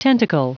Prononciation du mot tentacle en anglais (fichier audio)
Prononciation du mot : tentacle